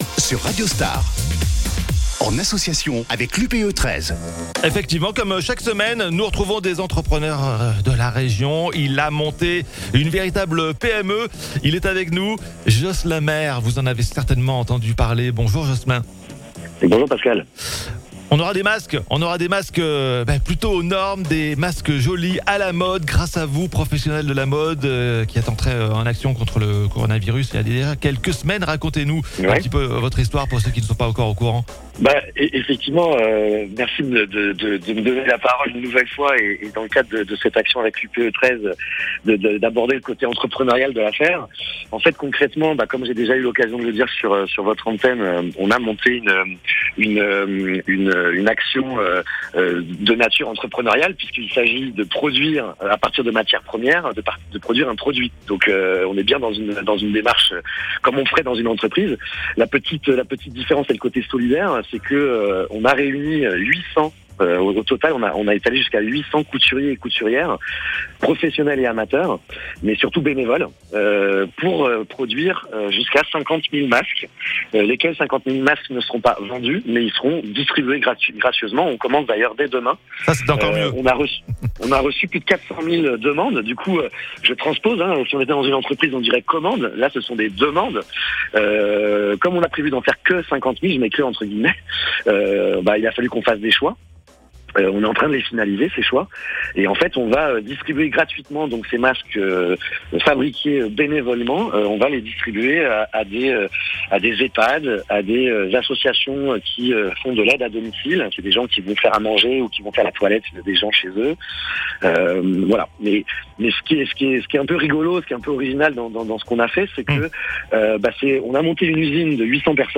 L’Upe 13, en partenariat avec Radio Star, vous propose "Parole d'entrepreneurs solidaires" et vous donne rendez-vous tous les mercredis dès 9h30 pour une émission spéciale Covid-19. Chaque semaine, nous vous invitons à découvrir les initiatives solidaires de chefs d’entreprise, adhérents de l'Upe 13, face au Covid-19.